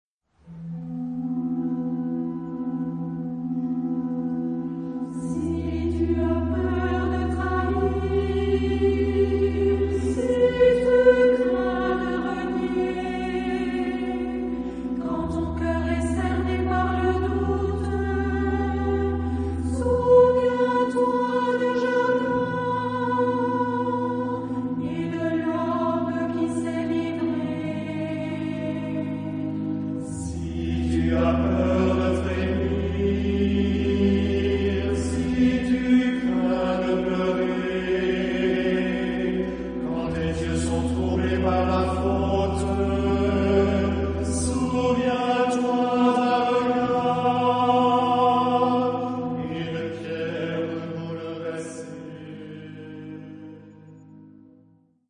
Genre-Style-Form: Hymn (sacred)
Mood of the piece: meditative ; calm
Type of Choir: unisson
Instrumentation: Organ
Tonality: F tonal center